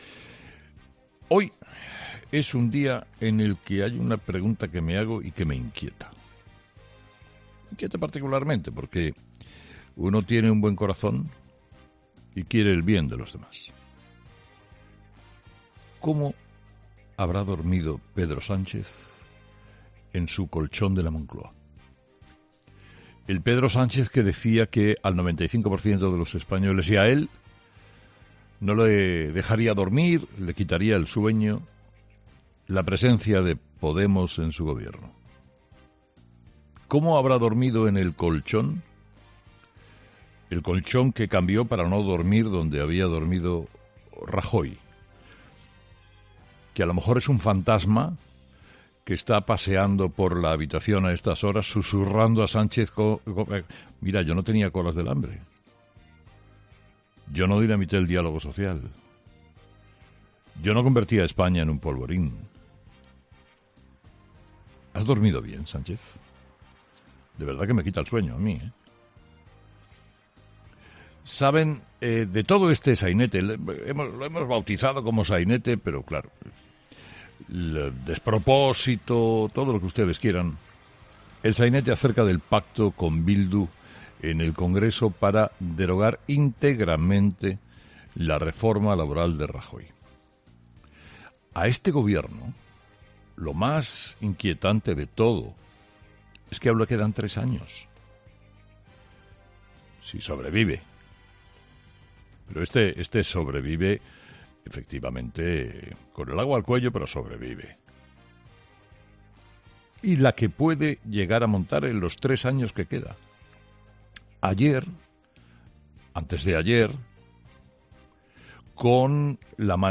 Carlos Herrera comenzó el programa del viernes 22 de mayo buscando respuestas a sus principales preguntas sobre el motivo por el que Pedro Sánchez ha decidido pactar con Bildu y Unidas Podemos para derogar de forma íntegra la reforma laboral del Gobierno Rajoy: "Hoy me hago una pregunta que me inquieta, ¿cómo habrá dormido Pedro Sánchez en su colchón de la Moncloa?